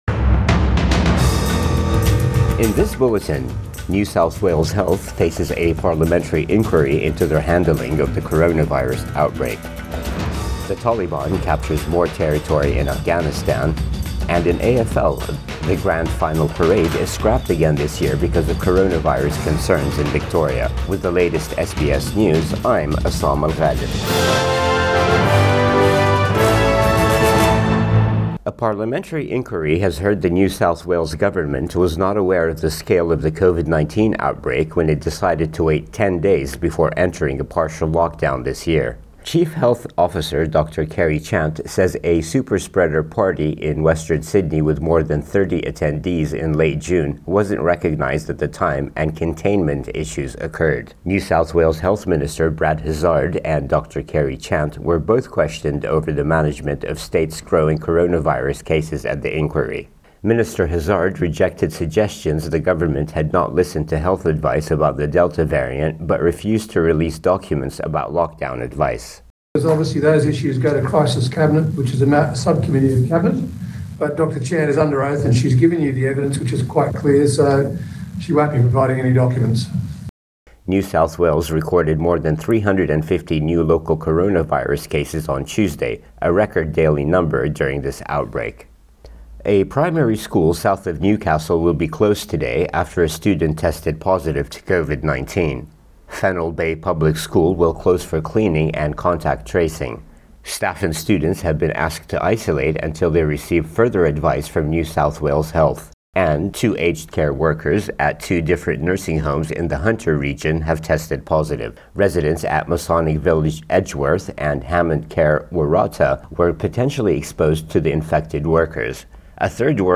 AM bulletin 11 August 2021